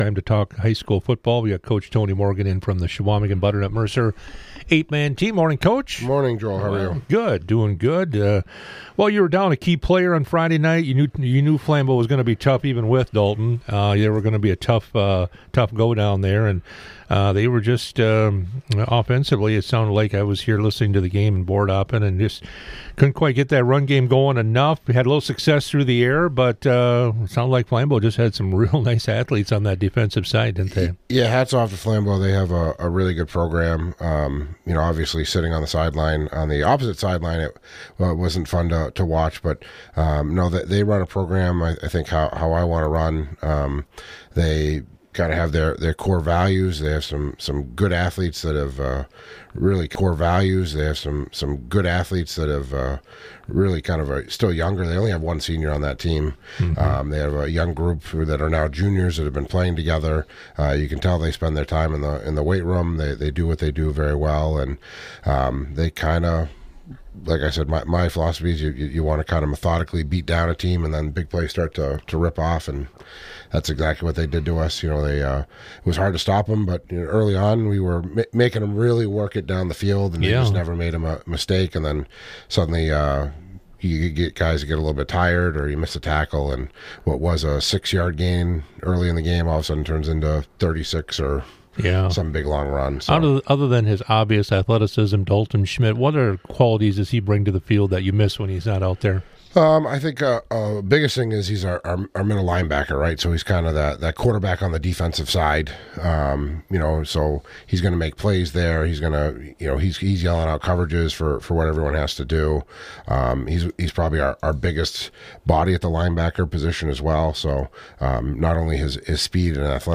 Interviews and special broadcasts from 98Q Country in Park Falls.